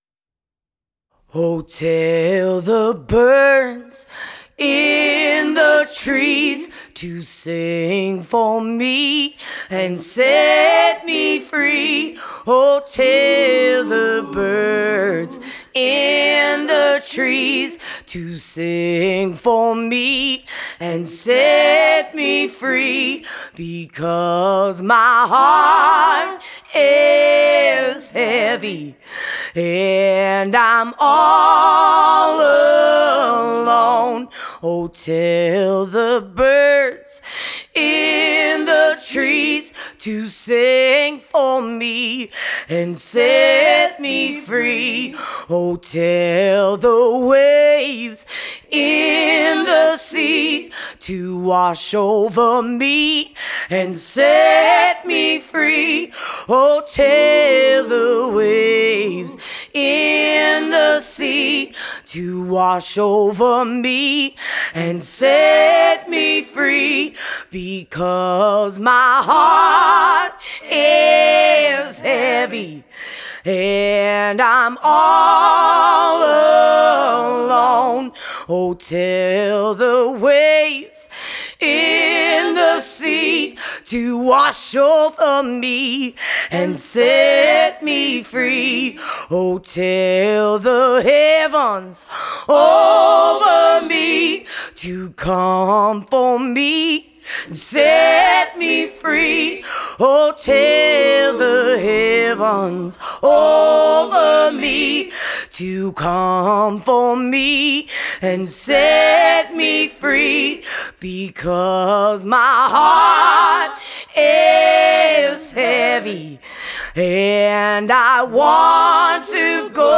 deep southern soul, roots/reggae and 50’s rock n roll
rhythm guitar and vocals
lead guitar
drums